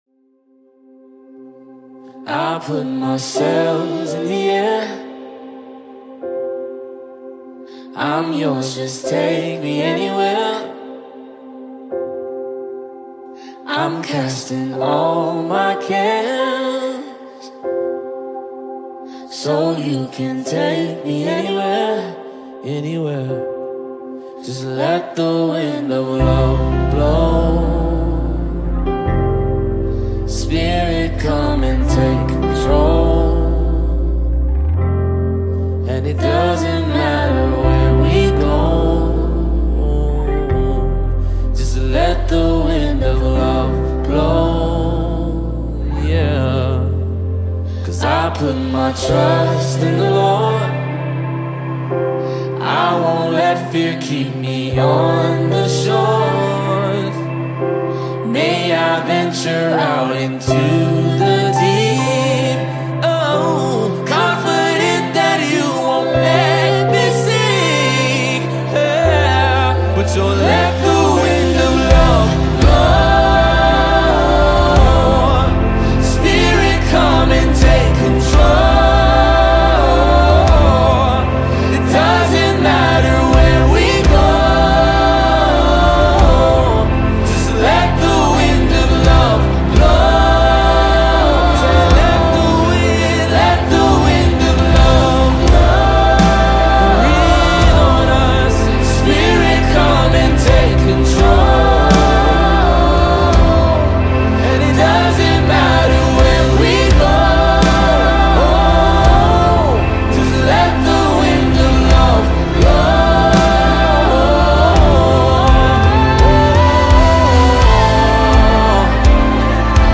ForeignLyricsMusicVideoWorship